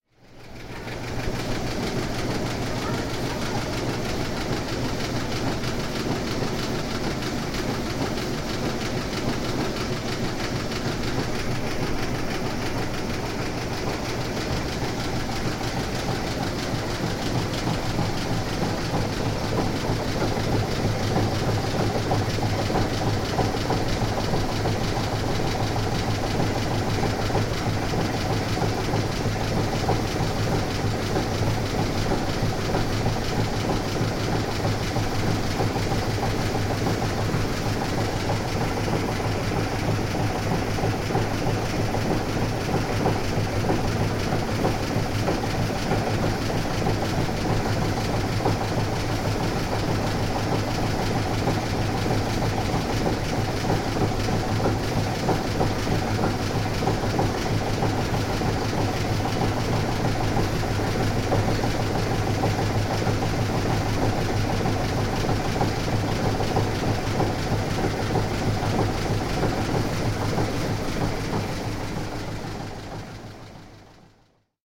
Machinery at the Embilmeegama tea factory